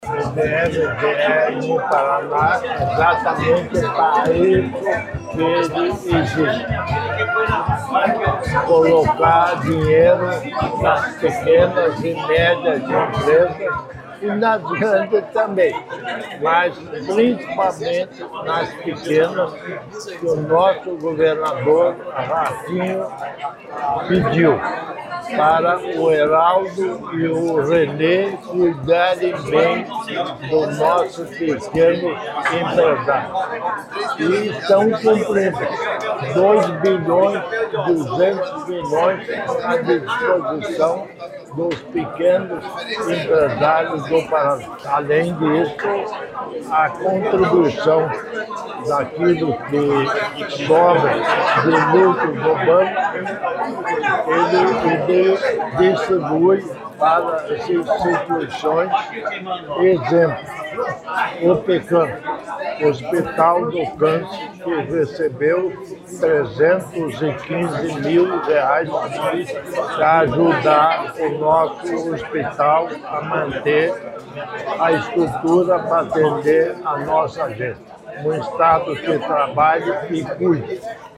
Sonora do governador em exercício, Darci Piana, sobre os convêncios do BRDE para beneficiar a região Oeste e Sudoeste
DARCI PIANA - SHOW RURAL.mp3